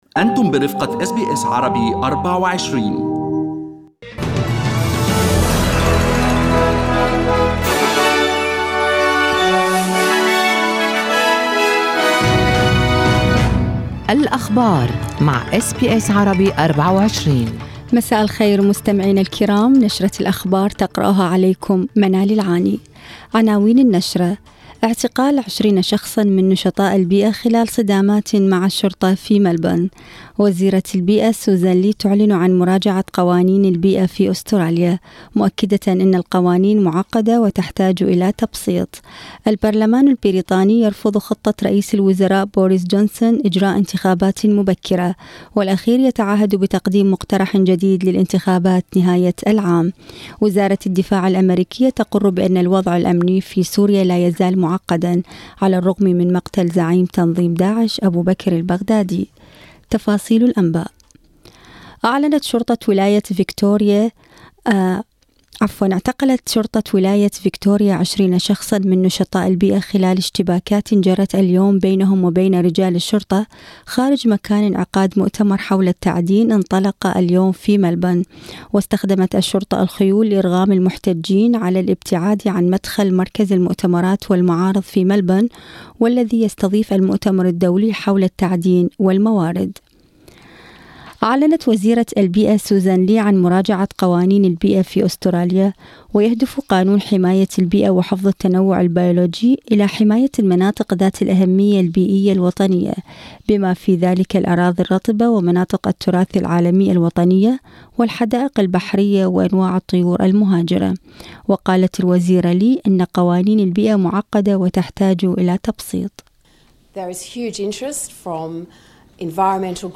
أخبار المساء: الحكومة ترفض زيادة ضريبة السلع والخدمات بسبب الجفاف